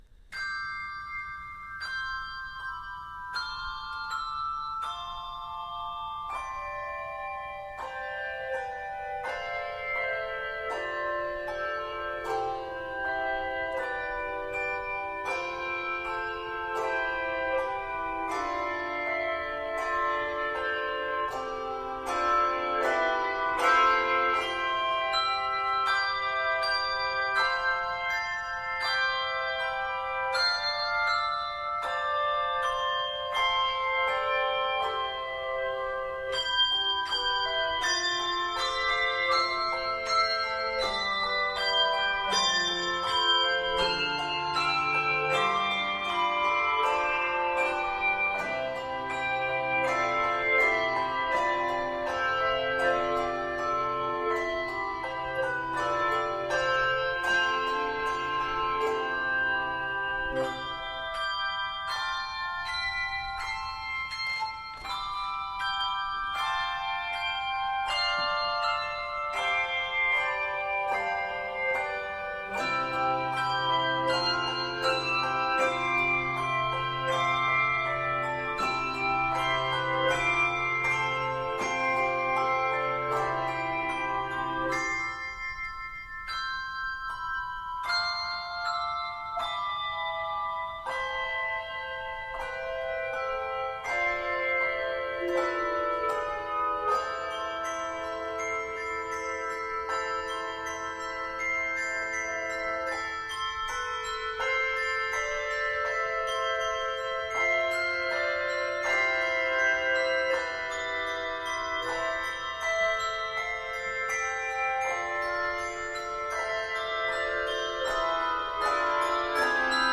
expressive medley
Octaves: 3-5